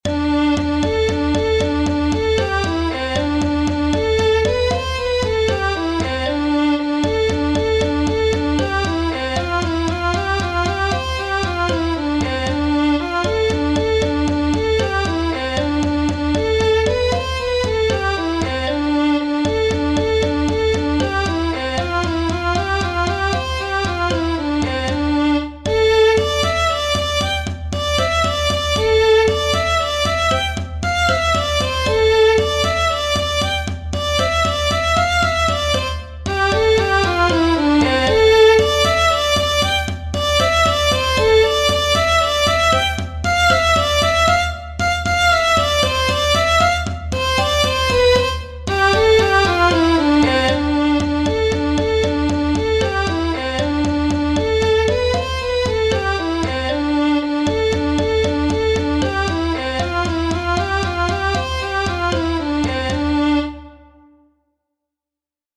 -3/8 time signatures give a lively and dance-like impression.
-This tune is composed based on the D minor scale.
Morrison's Jig is nowadays a famous traditional Irish tune that may be performed in both formal and informal gatherings.
The tune has a 3/8 time signature, whose lively and swinging feel makes it perfect for dancing. It frequently contains triplets and grace notes (a musical note added as an ornament and which is frequently drawn in a smaller way).